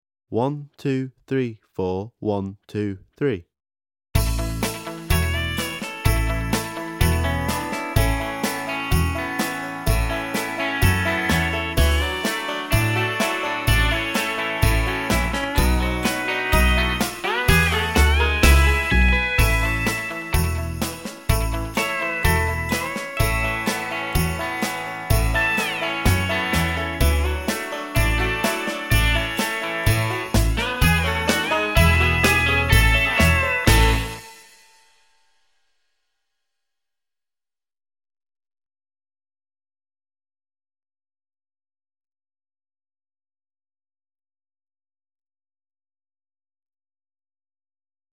An episode by Simon Balle Music